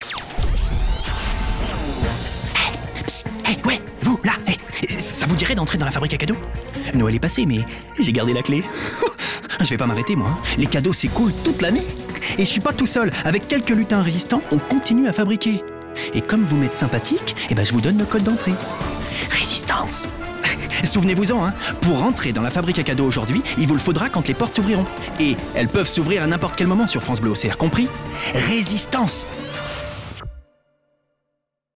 Personnages décalés
Jean, le lutin résistant: